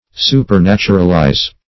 Meaning of supernaturalize. supernaturalize synonyms, pronunciation, spelling and more from Free Dictionary.
Search Result for " supernaturalize" : The Collaborative International Dictionary of English v.0.48: Supernaturalize \Su`per*nat"u*ral*ize\, v. t. To treat or regard as supernatural.